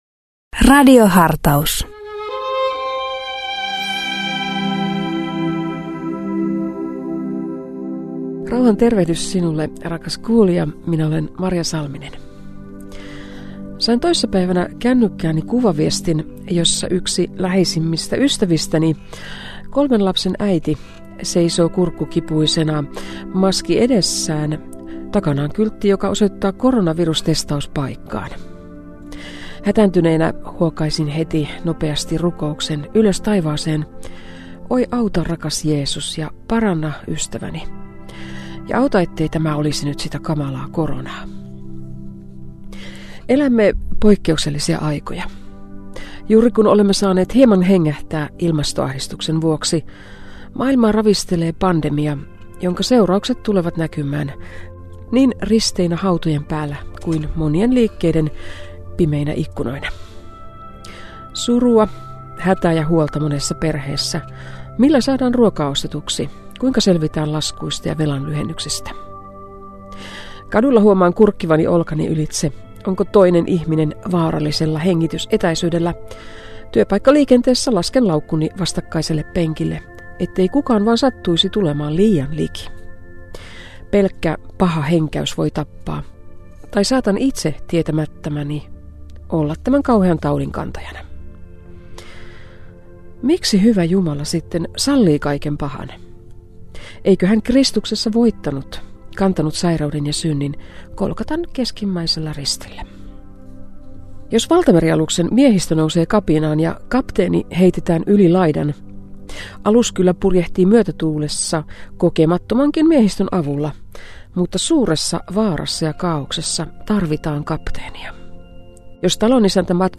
PostRadio Dei lähettää FM-taajuuksillaan radiohartauden joka arkiaamu kello 7.50. Radiohartaus kuullaan uusintana iltapäivällä kello 17.05.
Pääpaino on luterilaisessa kirkossa, mutta myös muita maamme kristillisen perinteen edustajia kuullaan hartauspuhujina.